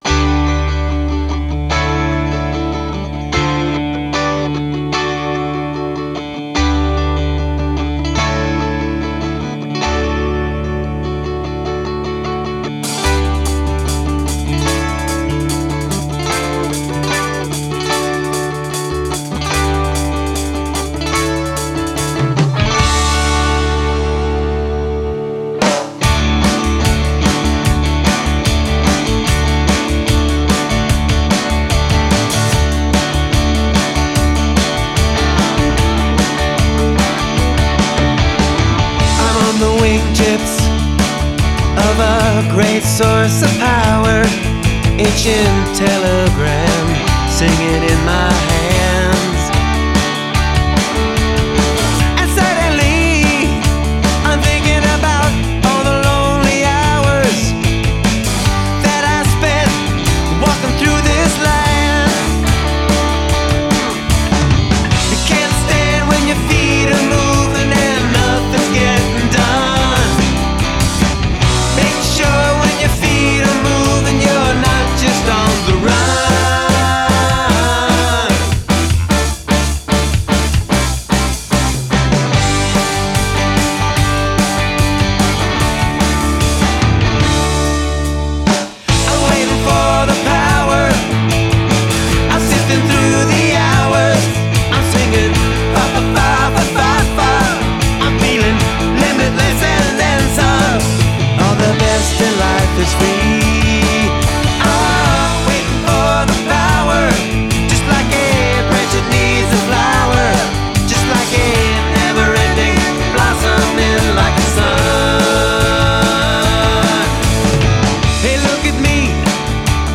Lounge around to some rock and roll music my friends